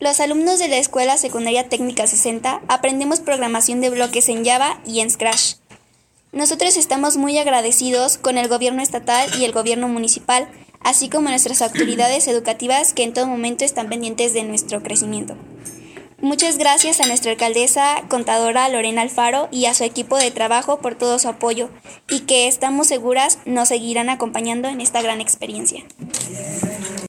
Lorena Alfaro García – Presidenta Municipal